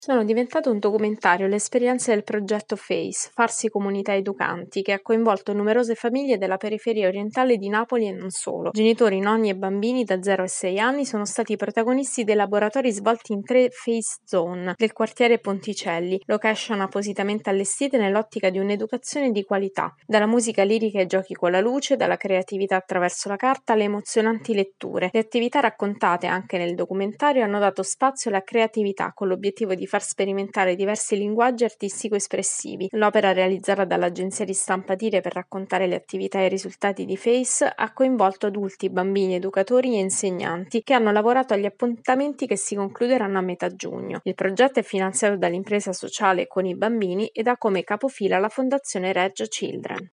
Un documentario racconta le esperienze contro la povertà educativa nella periferia orientale di Napoli. Il servizio